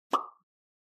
Free UI/UX sound effect: Retro Blip.
Retro Blip
# retro # blip # 8bit # game About this sound Retro Blip is a free ui/ux sound effect available for download in MP3 format.
587_retro_blip.mp3